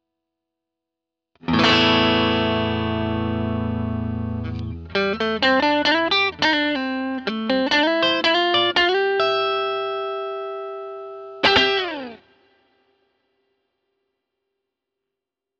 GT -1000coreとGX-1の同じアンプタイプにうっすらホールリバーブをかけた音でサンプルを録ってみました。
いずれもアンプシミュレーターとリバーブのみの音色です。
GT -1000core クリーン（アンプタイプ　NATURAL）
GT-1000core-clean.wav